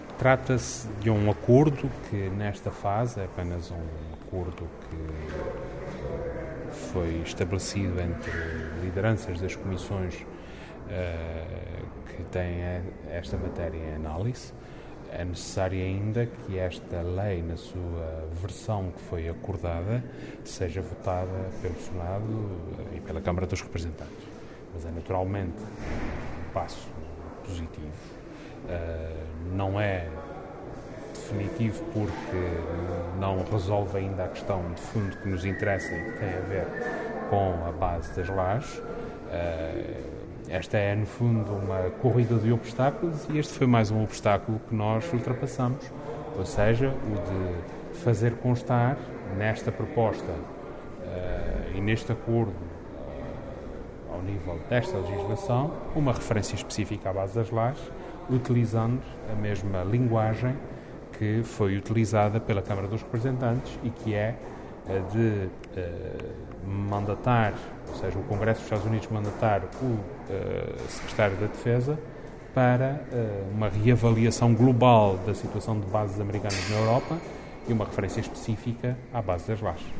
“Estamos a falar apenas de um passo. Essa é uma corrida de obstáculos e ultrapassamos, com sucesso, mais um obstáculo”, afirmou Vasco Cordeiro aos jornalistas, alertando para a necessidade de se ter cautela, já que essa referência às Lajes necessita ainda de ser aprovada pelos Congressistas e pelos Senadores norte-americanos.